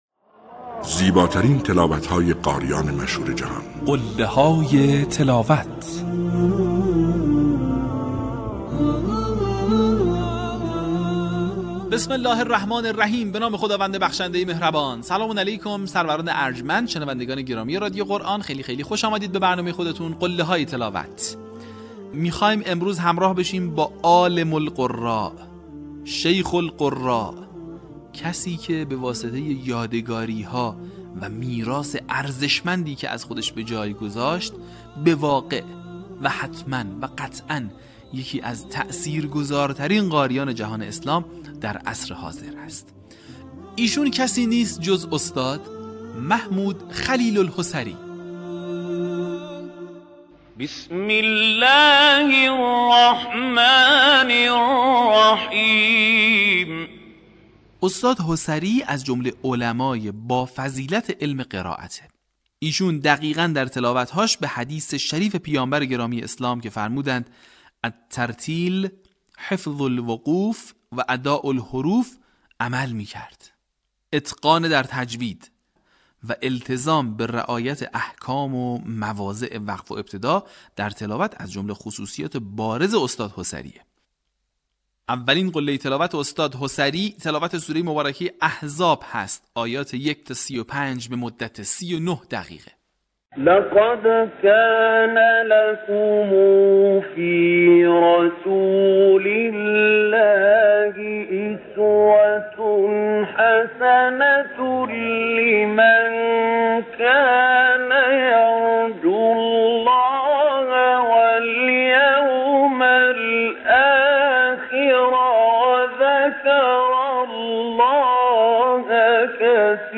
در قسمت پنجاه‌ودو فراز‌های شنیدنی از تلاوت‌های به‌یاد ماندنی استاد «خلیل الحصری» را می‌شنوید.
برچسب ها: خلیل الحصری ، قله های تلاوت ، فراز ماندگار ، تلاوت تقلیدی